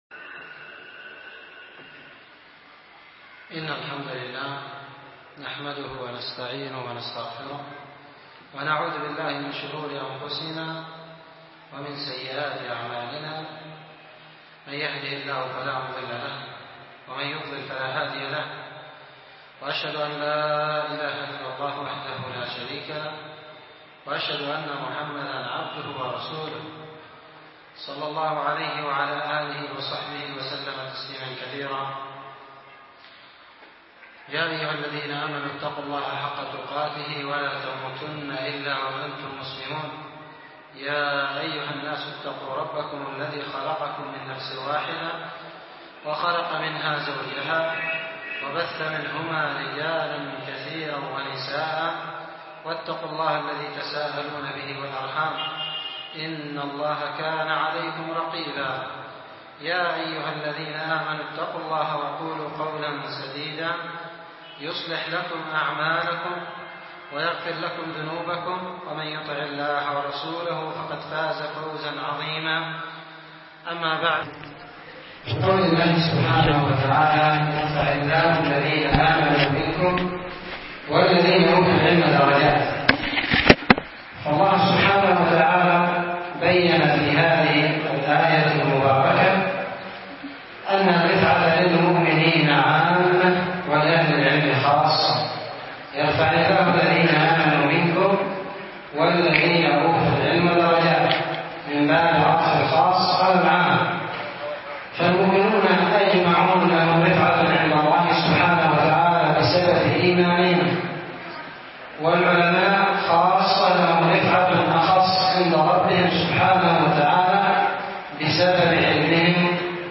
محاضره